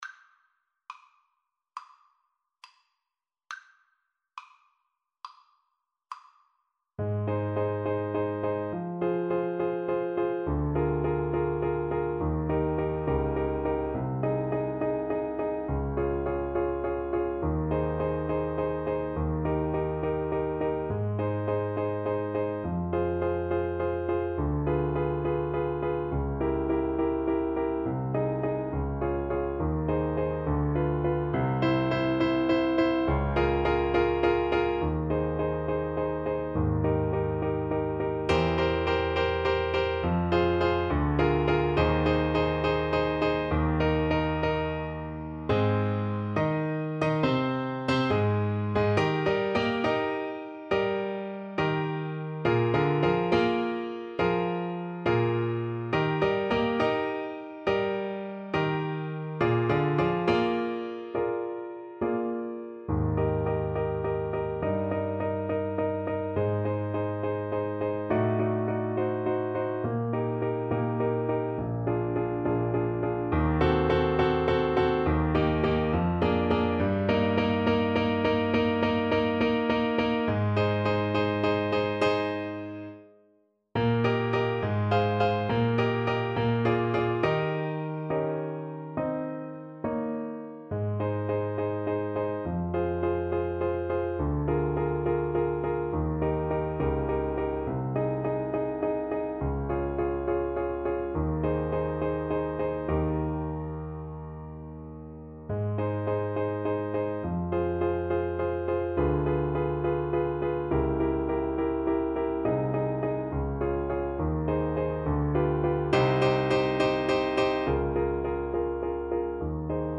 Play (or use space bar on your keyboard) Pause Music Playalong - Piano Accompaniment Playalong Band Accompaniment not yet available reset tempo print settings full screen
Ab major (Sounding Pitch) Eb major (French Horn in F) (View more Ab major Music for French Horn )
4/4 (View more 4/4 Music)
Classical (View more Classical French Horn Music)